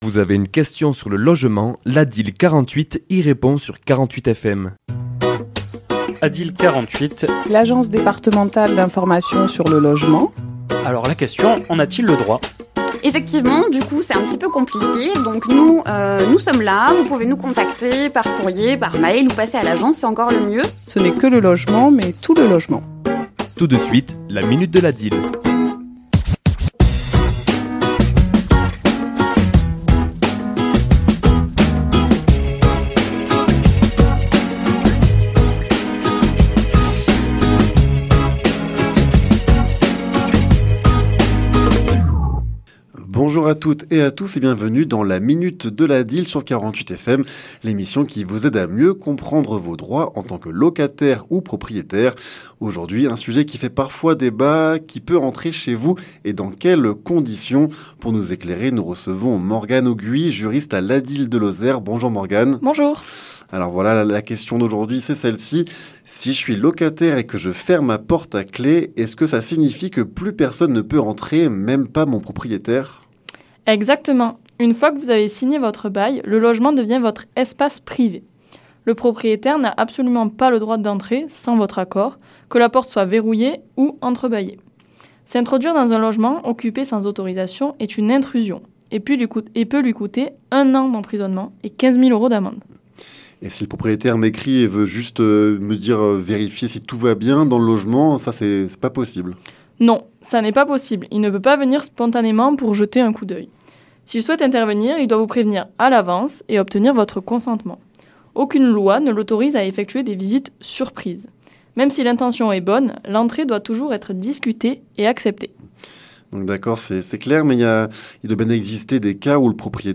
ChroniquesLa minute de l'ADIL